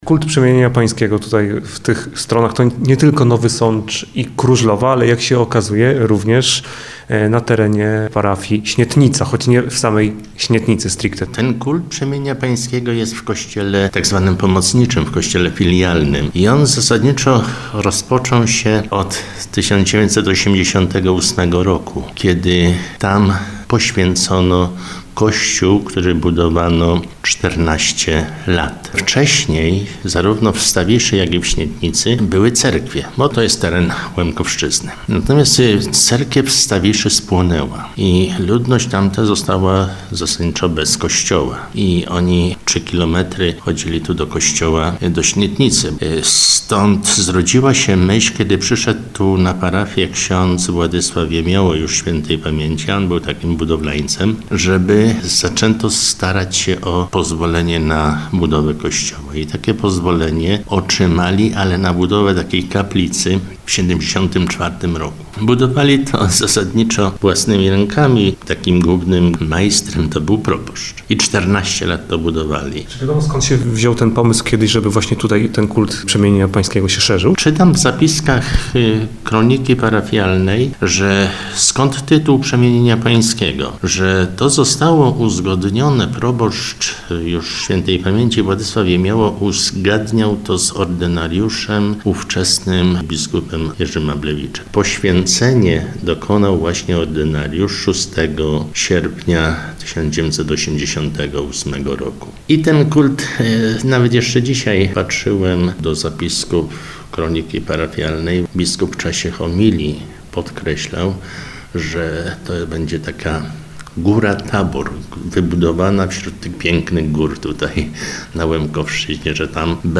6stawisza_rozmowa.mp3